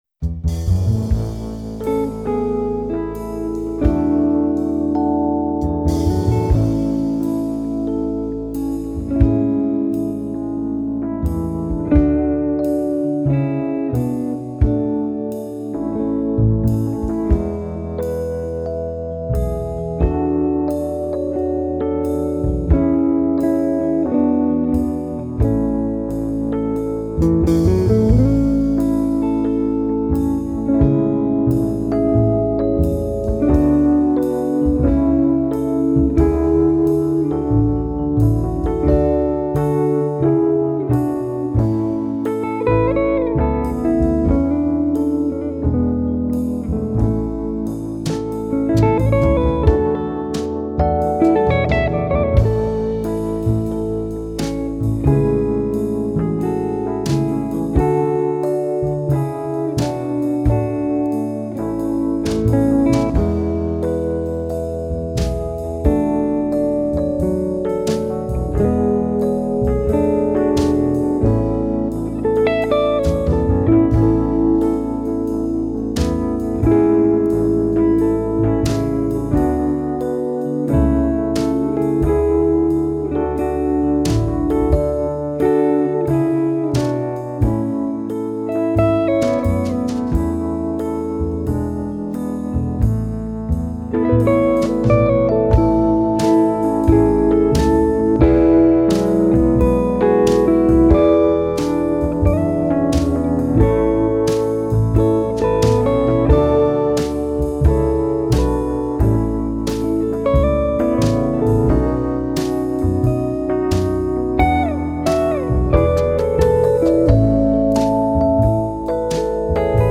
NoLeadVocal   Mark 11: 22 Easy Listening, Smooth Jazz Faith Guitar, strings
Have-Faith-in-God-NO-LEAD-VOCAL.mp3